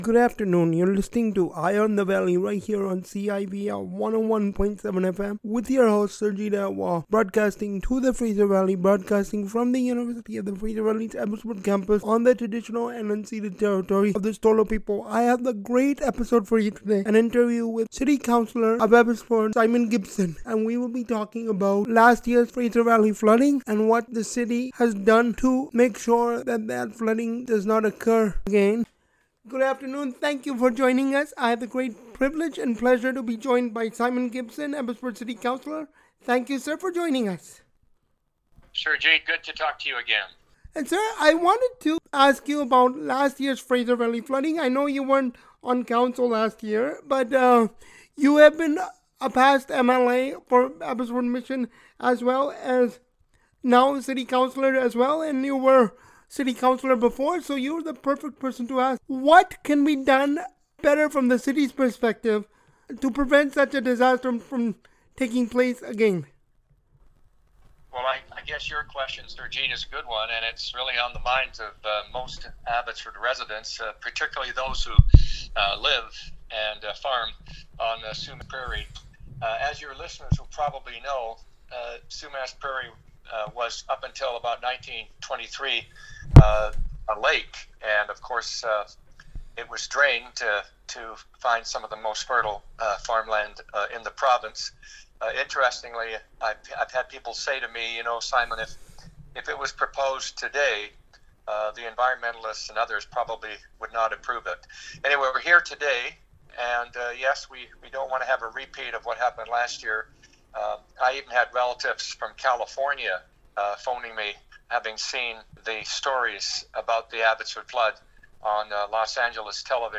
simon-gibson-interview.mp3